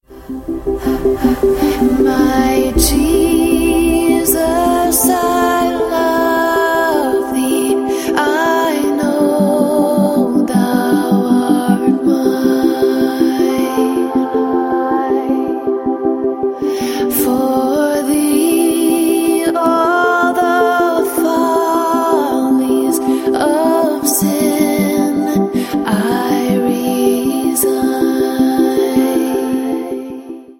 Your favorite Hymns - Set to a Vibey, Chillout Beat
• Sachgebiet: Dance